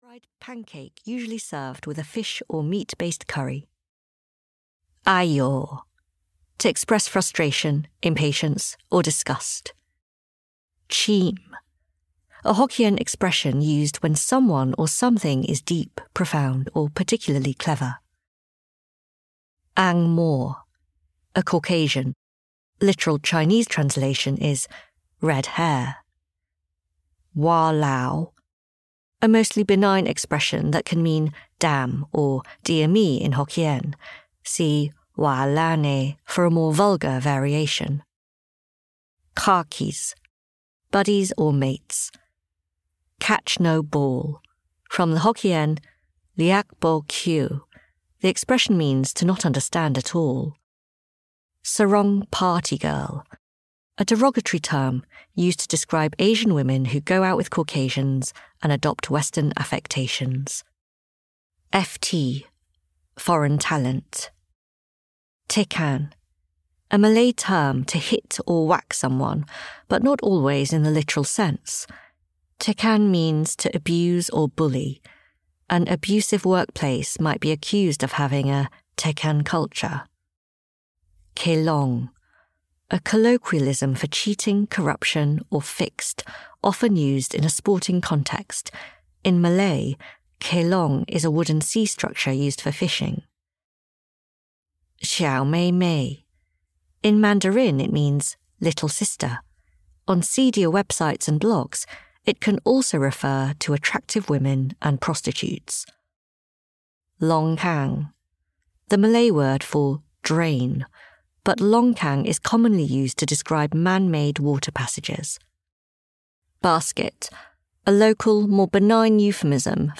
Audio knihaRich Kill, Poor Kill (EN)
Ukázka z knihy